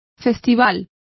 Complete with pronunciation of the translation of festival.